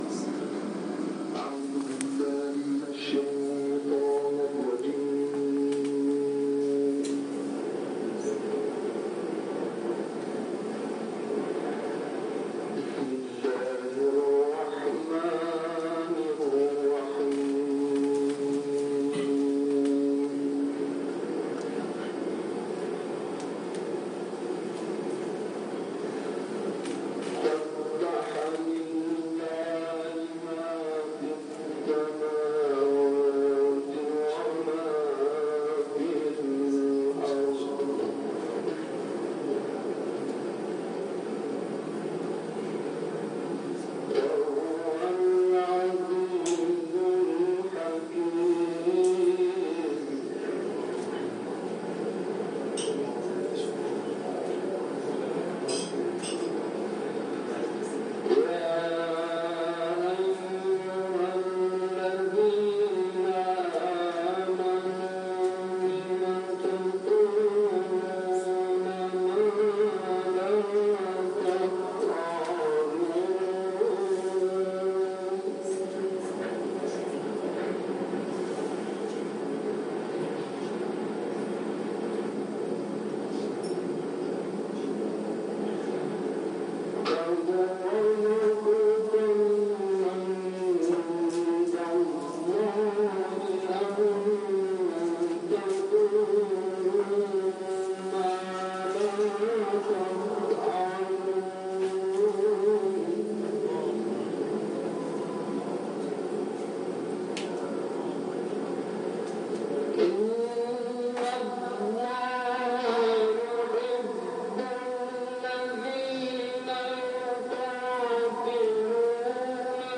البته او به تربیت قرآنی  و دینی خویش نیز همت گماشته و فعالیت‌های قرآنی خود را در مسجد محله آغاز کرده و چیزی نمی‌گذرد که به یک مربی قرآنی که معمولاً هم از سبک تلاوت استاد منشاوی تقلید می‌کرد، تبدیل می‌شود.
تلاوت